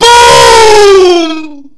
BoomVery.ogg